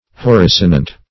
horrisonant - definition of horrisonant - synonyms, pronunciation, spelling from Free Dictionary
Horrisonant \Hor*ris"o*nant\, a.